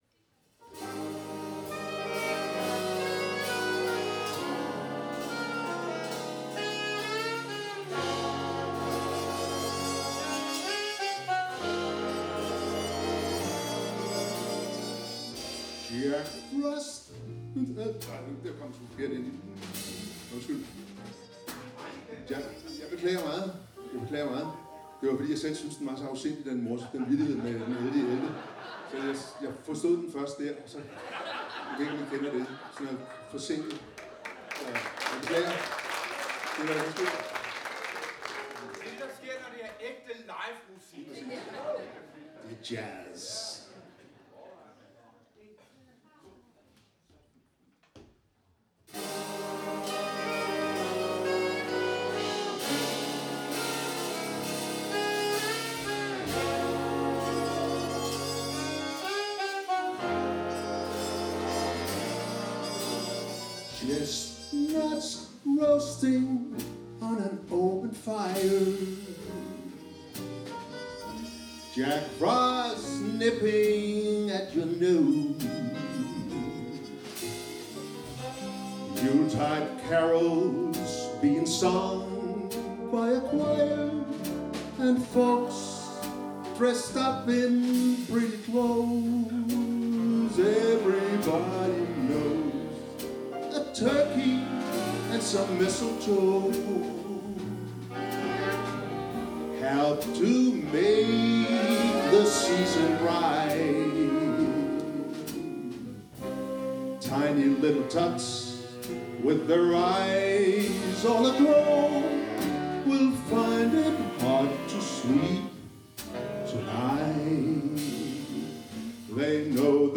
Vi garanterer, at I kommer i julestemning når I hører de jazzsvingende juleklassikere, flere af dem med vokal-indslag.
Udstyret er én digital stereo mikrofon, ikke en studieoptagelse !